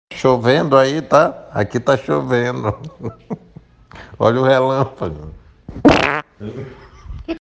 Chovendo aí? Aqui tá chovendo, olha o relâmpago (som de peido).
olha-o-relampago-peido.mp3